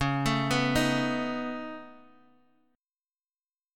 Listen to Db7sus2#5 strummed